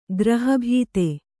♪ graha bhīte